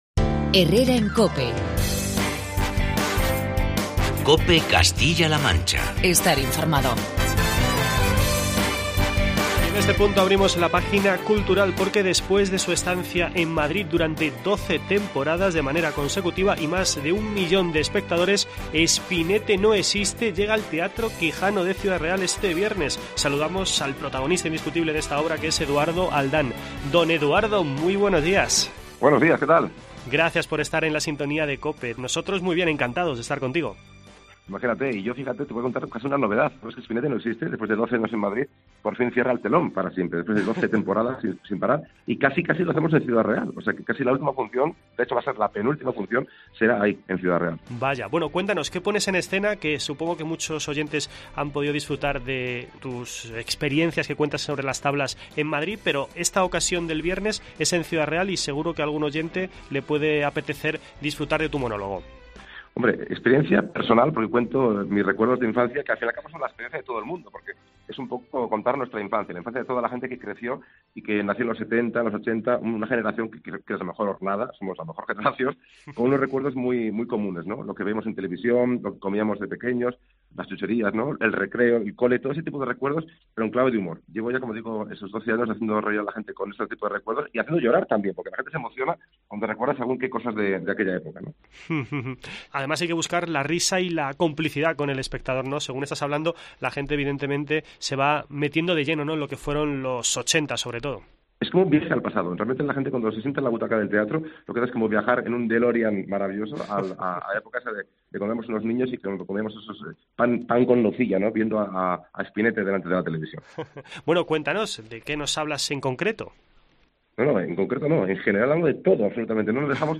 Entrevista con Eduardo Aldán